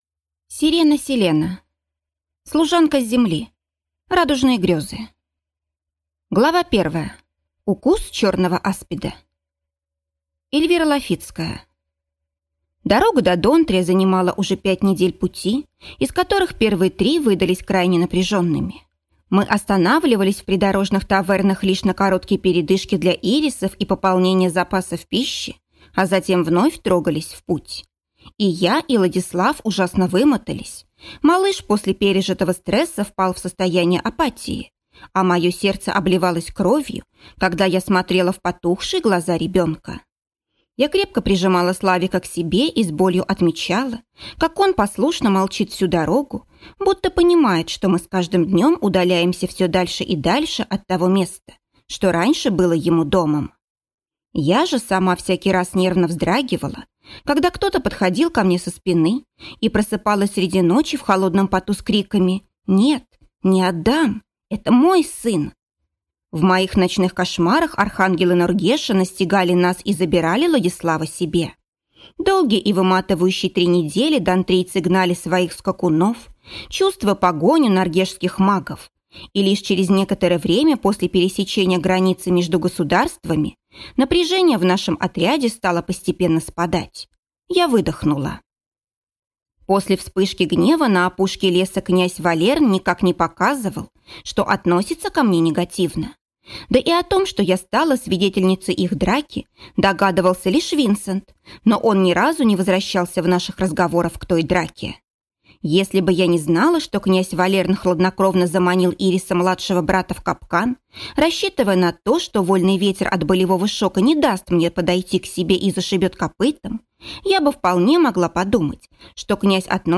Аудиокнига Служанка с Земли: Радужные грёзы | Библиотека аудиокниг